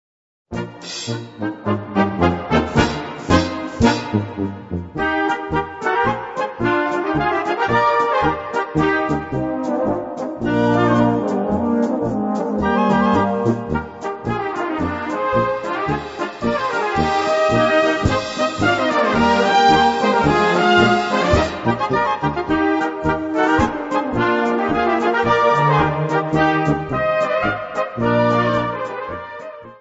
Gattung: Polkalied
2:28 Minuten Besetzung: Blasorchester Zu hören auf